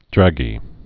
(drăgē)